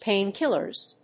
/ˈpeˌnkɪlɝz(米国英語), ˈpeɪˌnkɪlɜ:z(英国英語)/